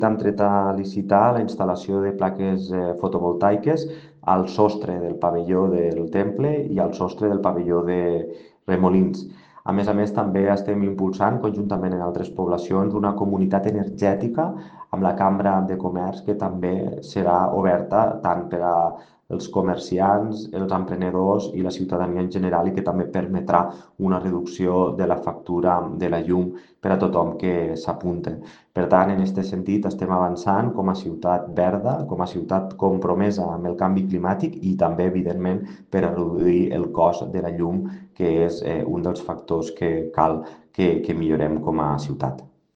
L’alcalde de Tortosa, Jordi Jordan, ha destacat la voluntat del govern municipal està compromès amb la lluita contra el canvi climàtic i ha recordat el projecte que impulsa l’Ajuntament de Tortosa  conjuntament amb altres localitats i la Cambra de Comerç de Tortosa han creat la Comunitat Energètica Local (CEL) Cambra Ebre Energia per promoure entre la ciutadania el consum d’energia renovable…